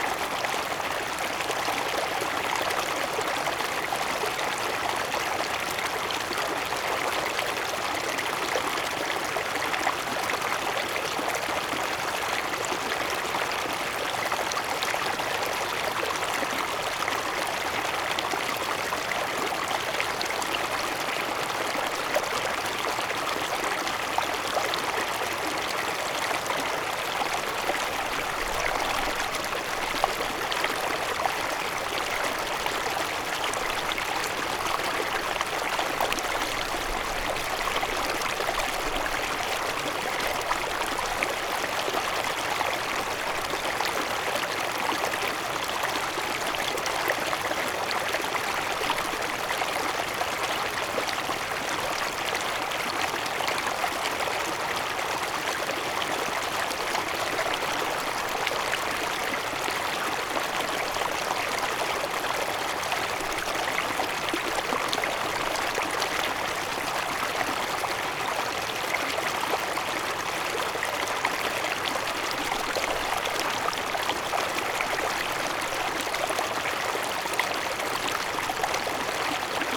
Звук текущей воды. Белый шум Водопада и Пение Птиц для новорожденных
• Категория: Каскад водопада
• Качество: Высокое